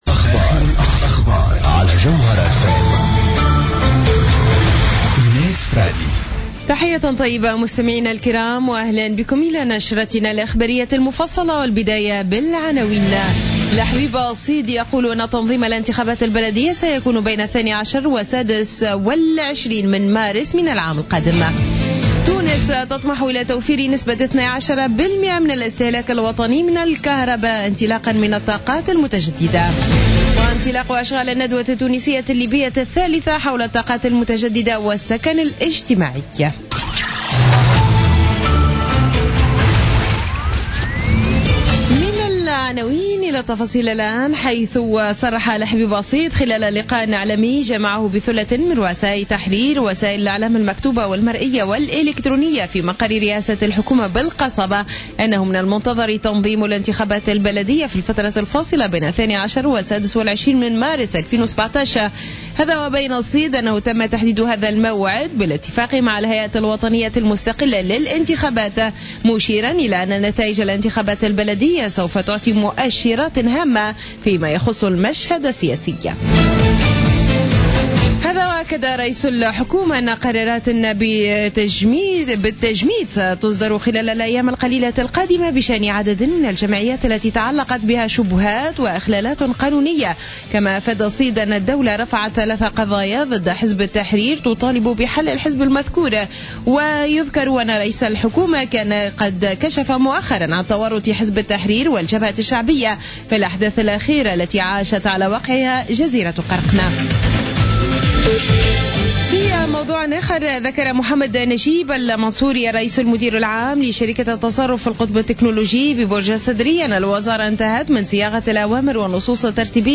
نشرة أخبار السابعة مساء ليوم السبت 23 أفريل 2016